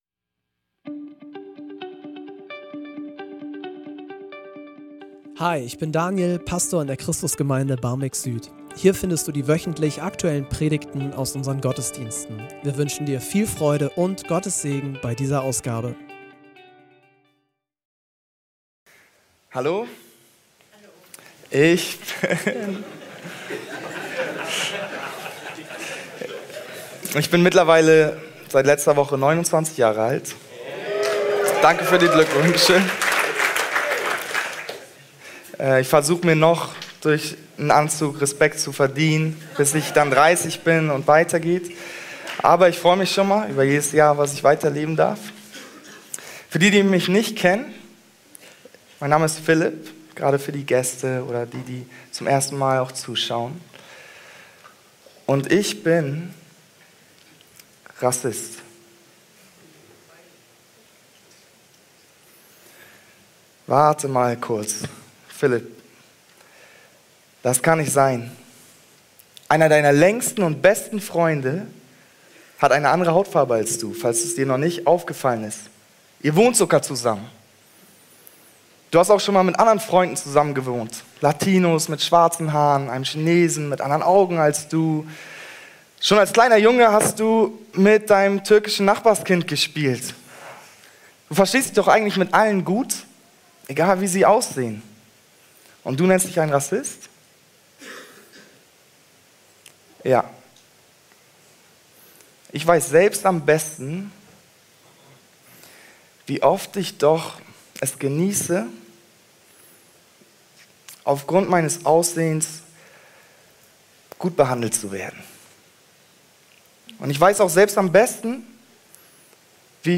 Predigtreihe